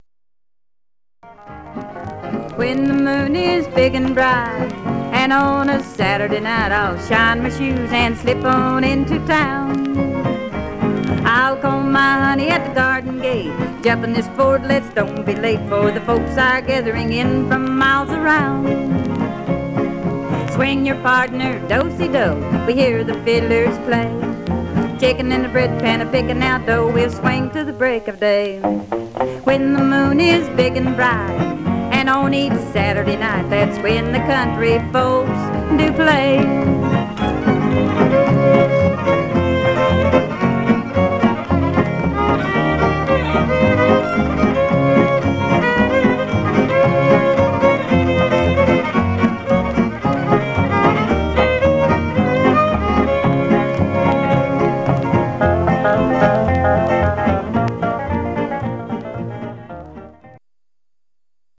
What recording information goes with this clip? Some surface noise/wear Mono